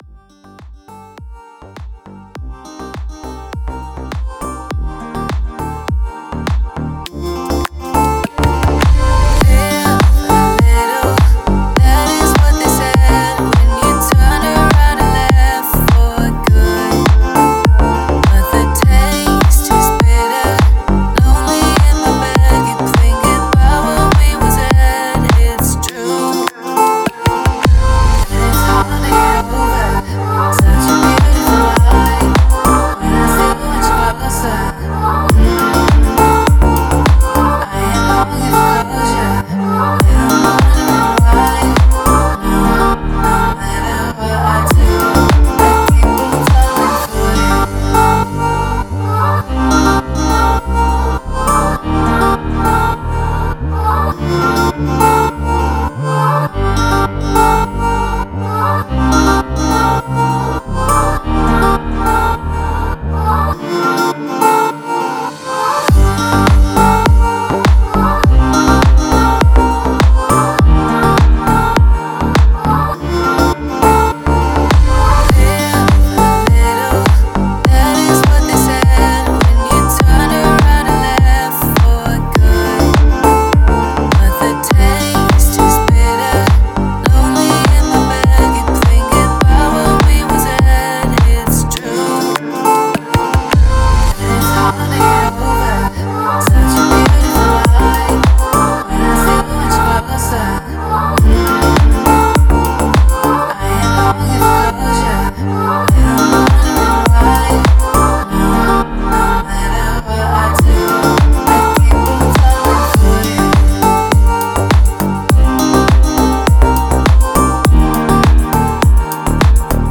Deep House музыка